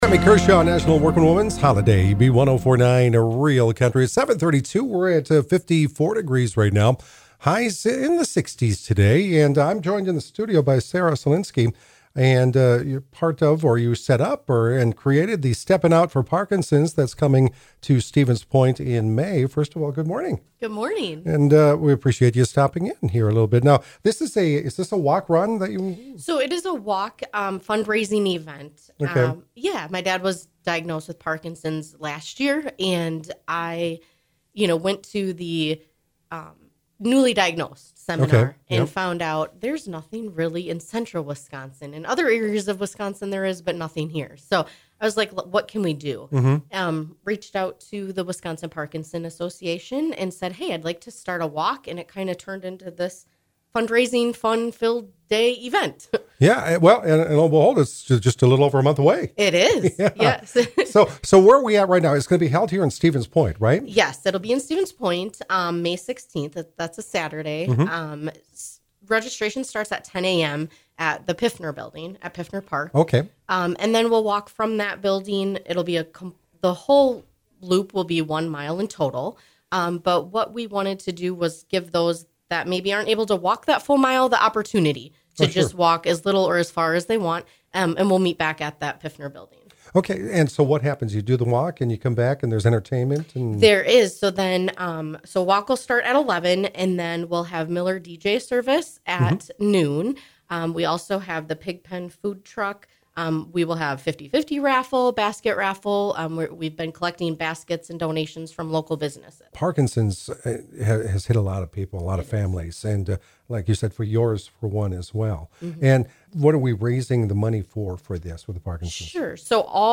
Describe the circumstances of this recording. stopped by the B1049 studio to chat